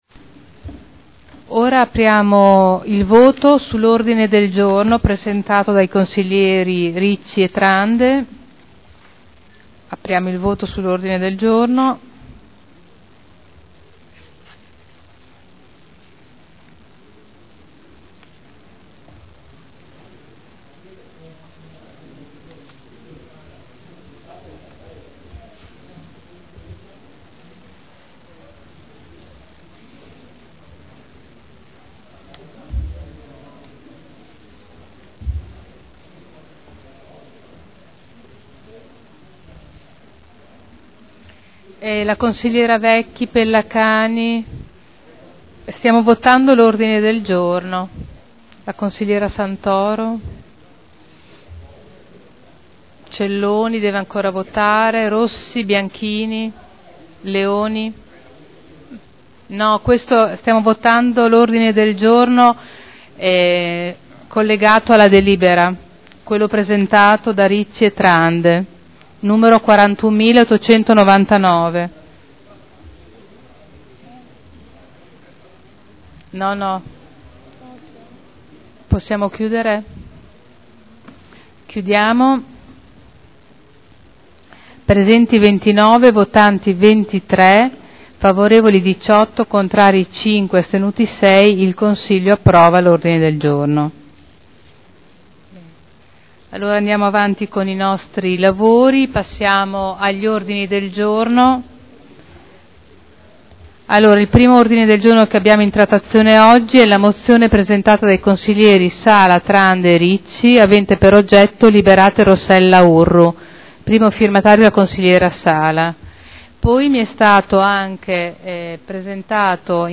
Presidente — Sito Audio Consiglio Comunale
Seduta del 12/04/2012. Mette ai voti l'ordine del giorno 41899.